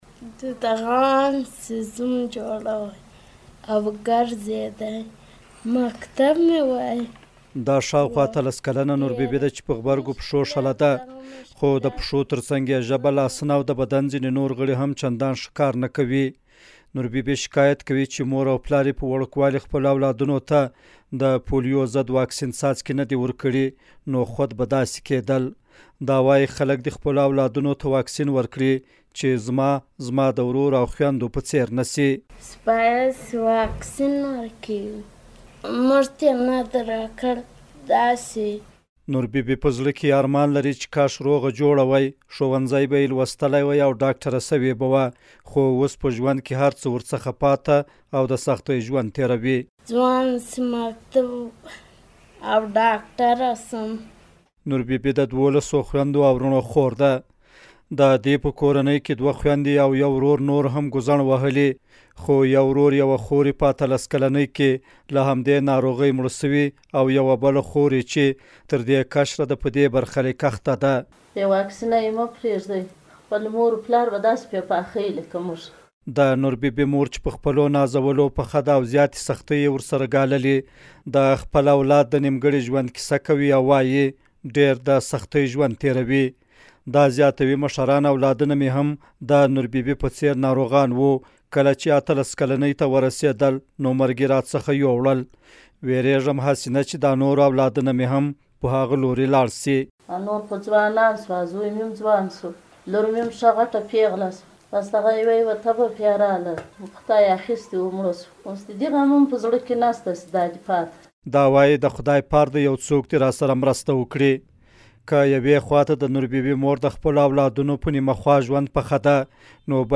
د غزني ولایت راپور